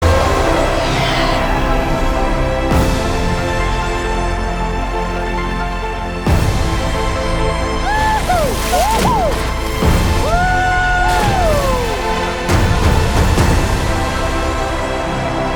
Avatar Drums